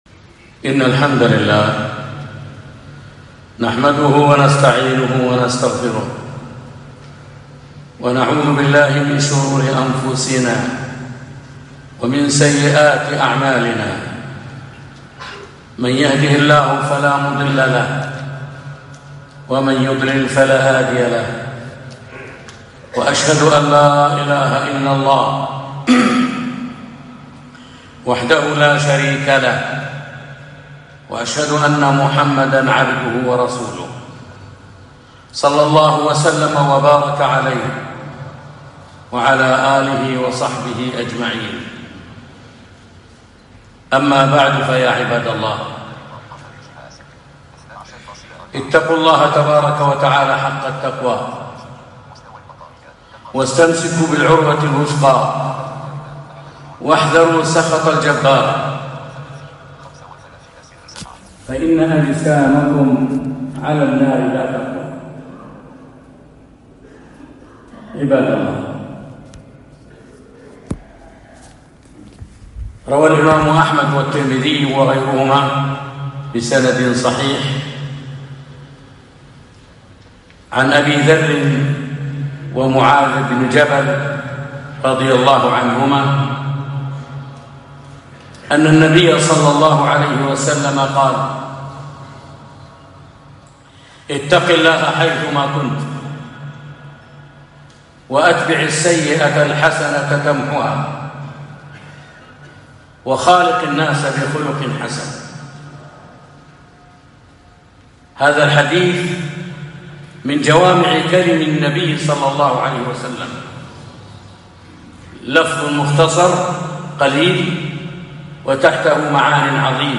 خطبة - اتق الله حيثما كنت